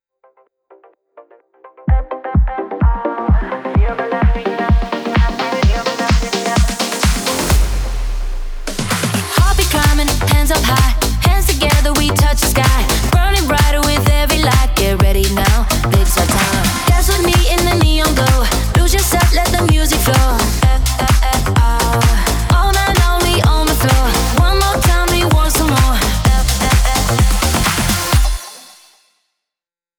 Generating music with prompt: Upbeat electronic dance music...